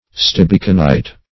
Search Result for " stibiconite" : The Collaborative International Dictionary of English v.0.48: Stibiconite \Stib"i*co*nite\, n. (Min.) A native oxide of antimony occurring in masses of a yellow color.